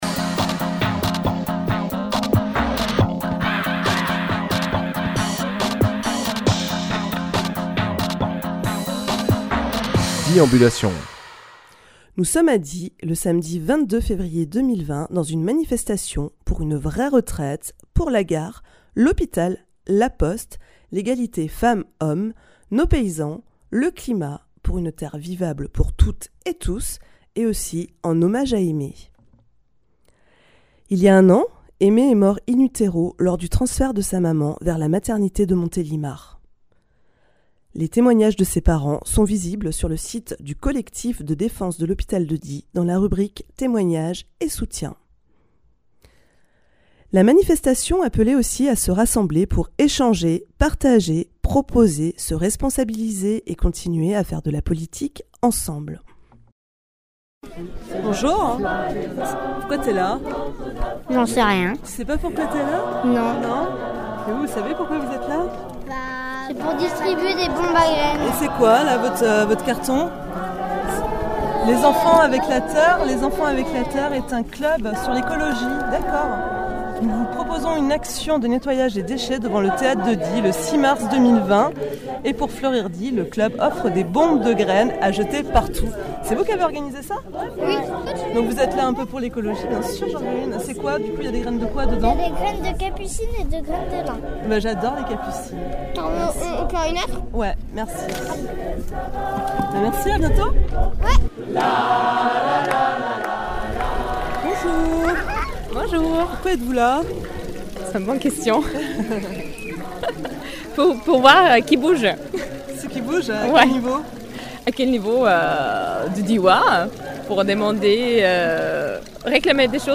Emission - Reportages Die, le 22 février 2020 Publié le 8 mars 2020 Partager sur…